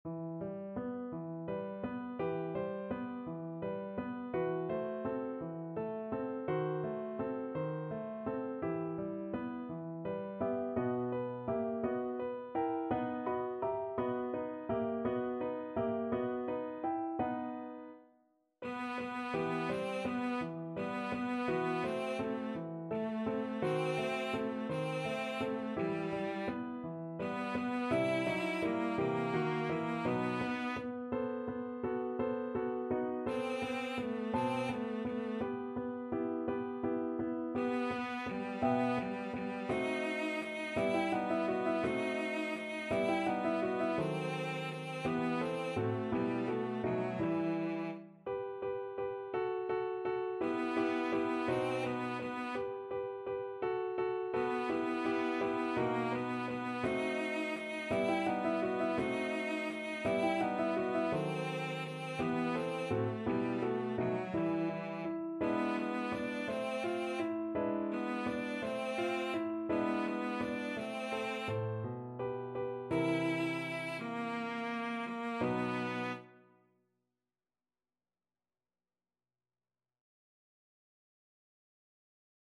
6/8 (View more 6/8 Music)
. = 56 Andante
E4-E5
Classical (View more Classical Cello Music)